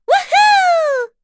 Dosarta-Vox_Happy4.wav